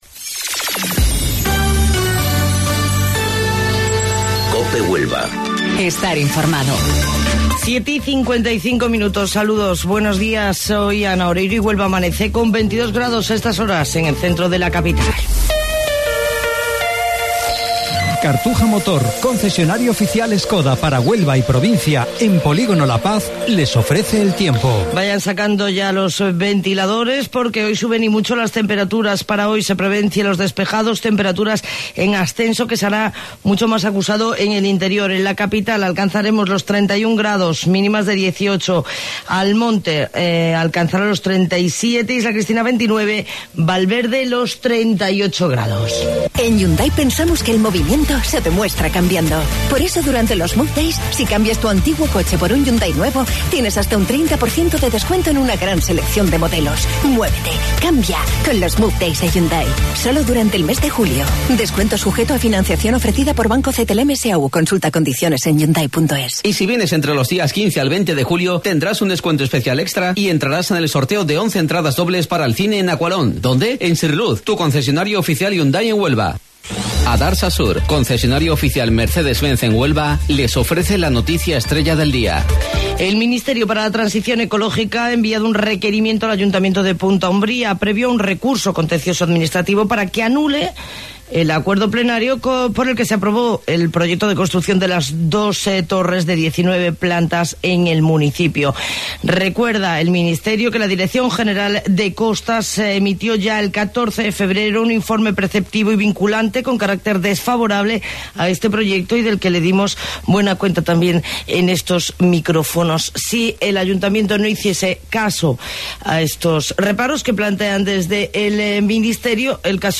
AUDIO: Informativo Local 07:55 del 11 de Julio